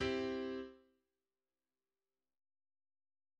A mód alapja a három hangból álló dúr terc.
Példák gyakori akkordokra:
{ \chordmode { c } }